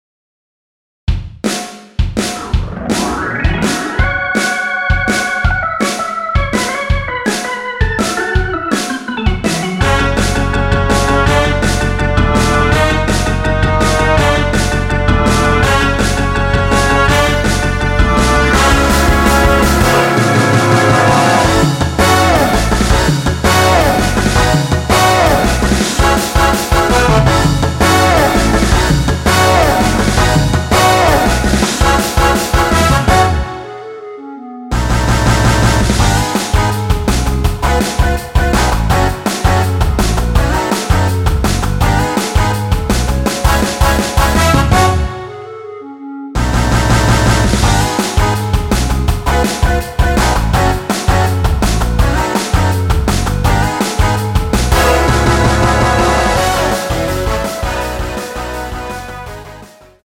원키에서(-1)내린 멜로디 포함된 MR입니다.
앞부분30초, 뒷부분30초씩 편집해서 올려 드리고 있습니다.